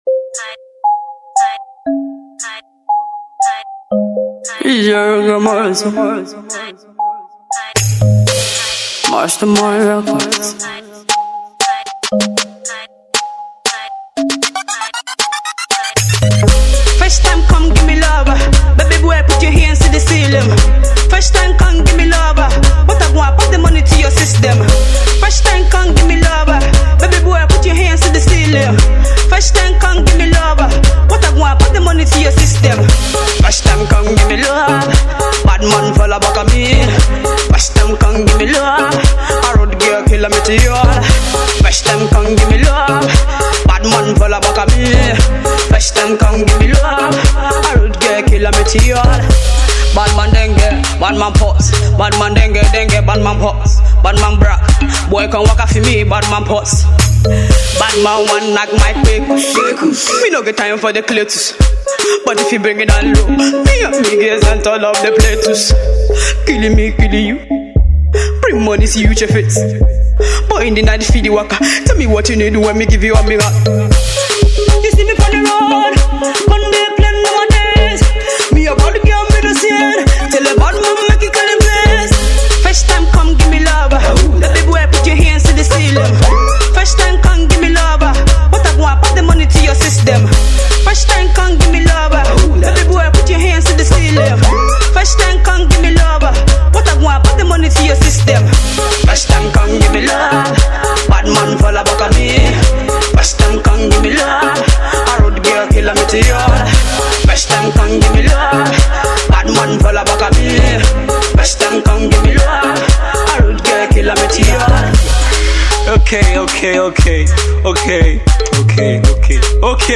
club banger